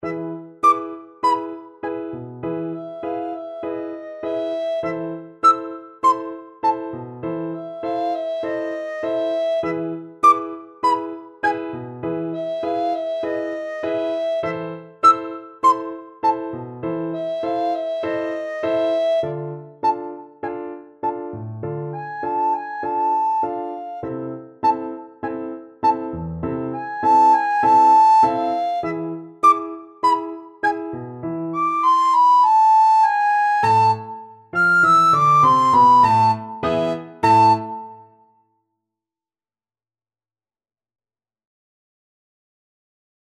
Soprano (Descant) Recorder version
4/4 (View more 4/4 Music)
Tempo di Tango
Recorder  (View more Easy Recorder Music)